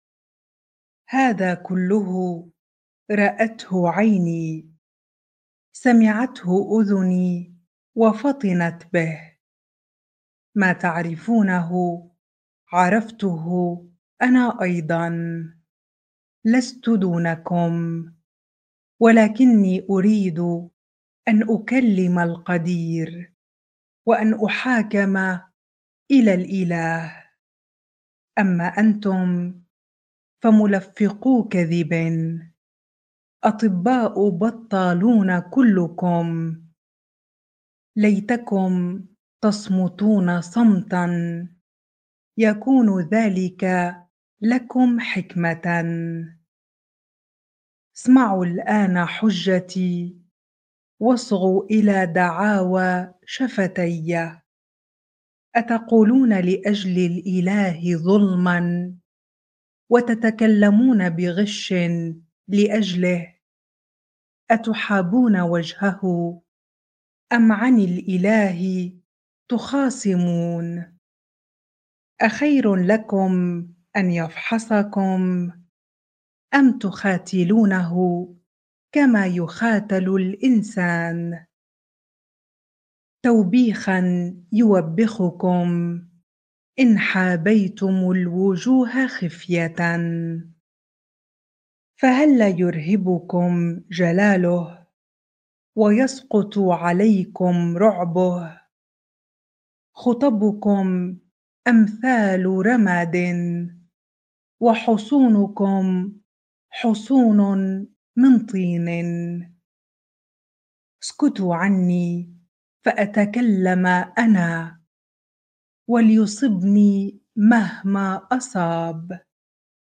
bible-reading-Job 13 ar